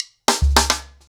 HarlemBrother-110BPM.33.wav